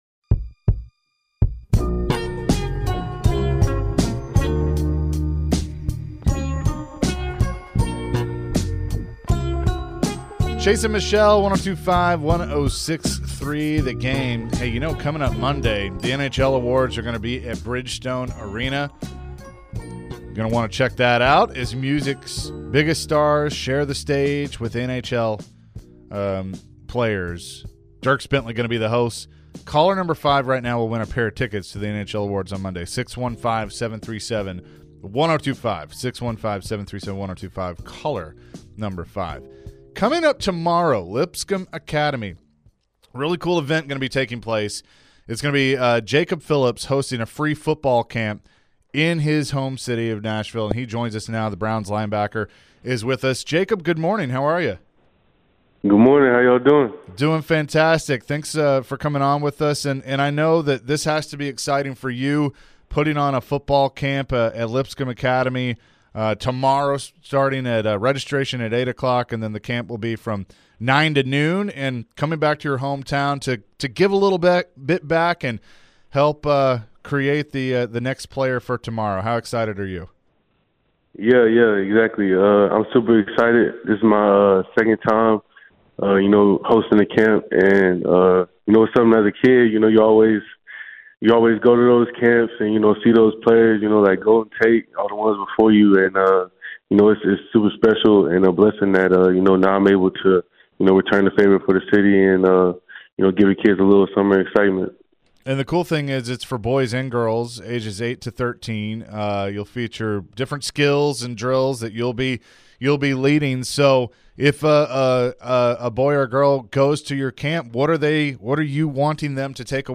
Jacob Phillips Interview (6-23-23)